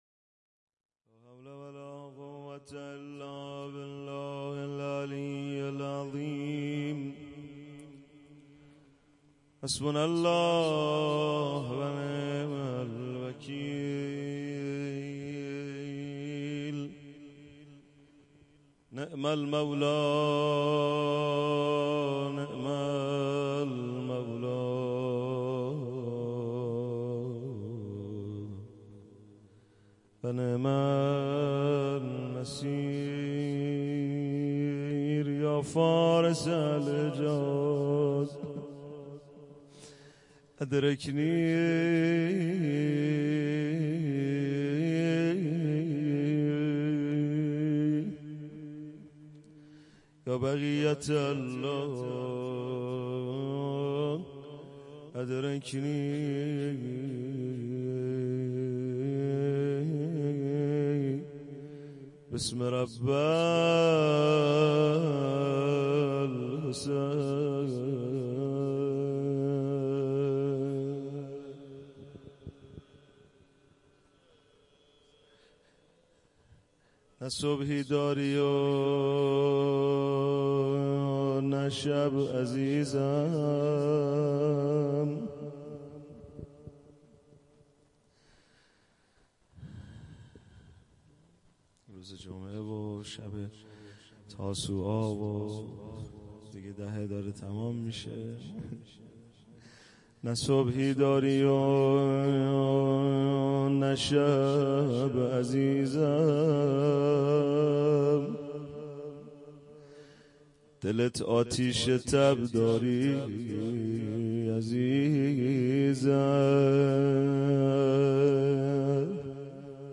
روضه
شب تاسوعا محرم